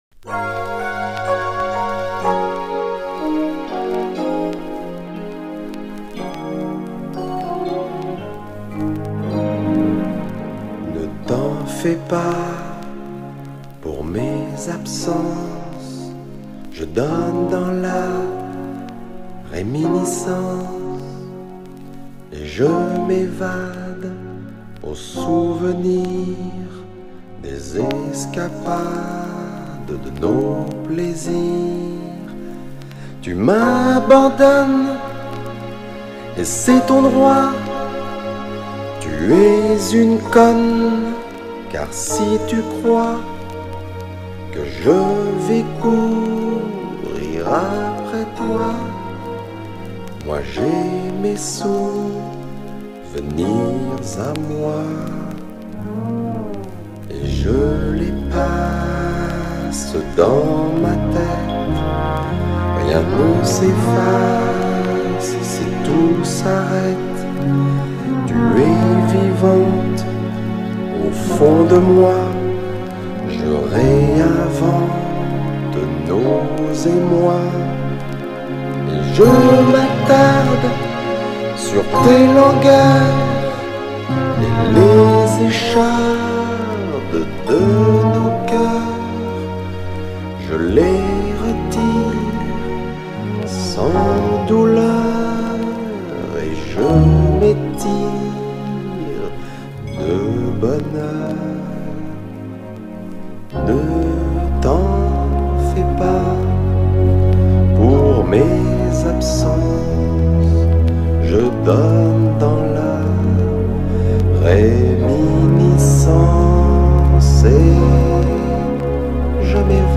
Сделал для себя открытие,узнал что он еще и поет.